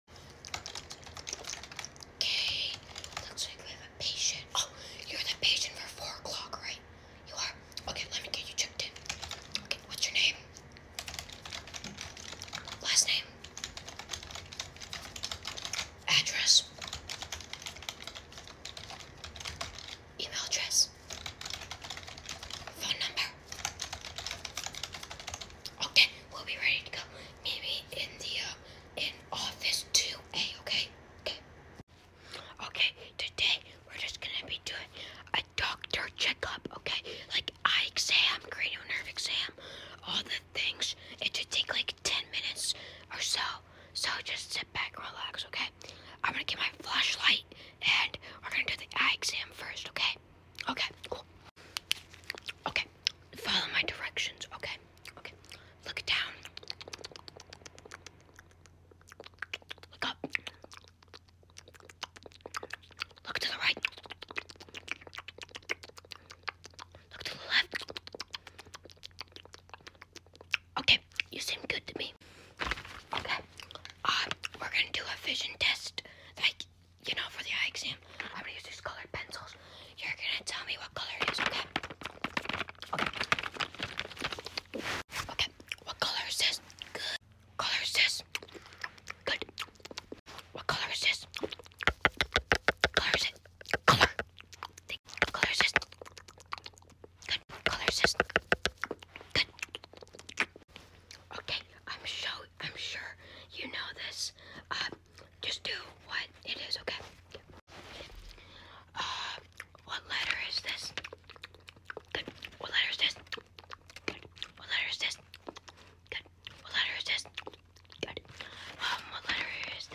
ASMR doctor’s appointment! sound effects free download